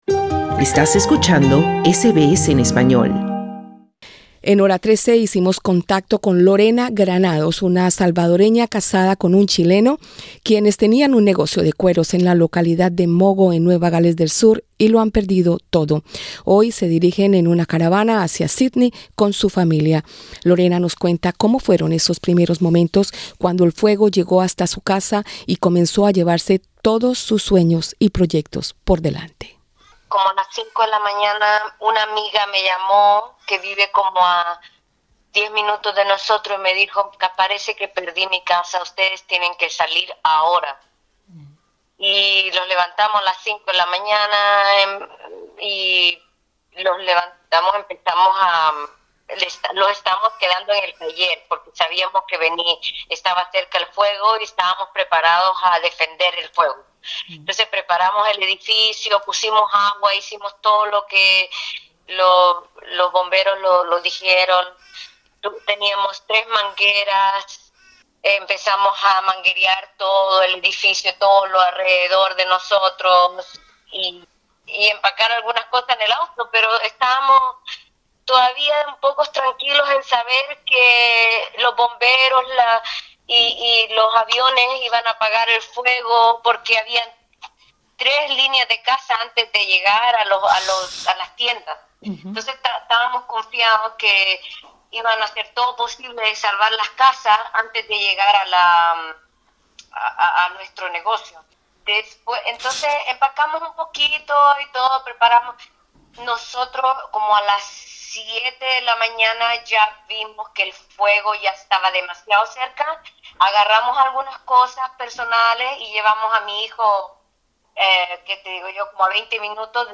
Una pareja de latinoamericanos residente en la costa meridional de Nueva Gales del Sur ha perdido no sólo su hogar, sino además un próspero negocio de cueros y artesanías. En entrevista para SBS español